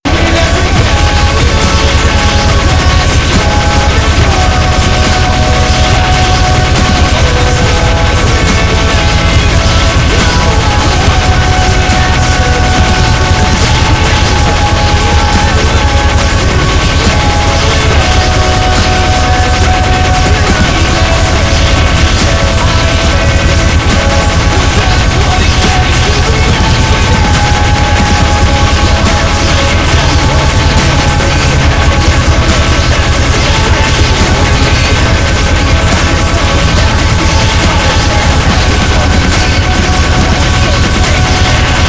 minifun_shoot_crit.wav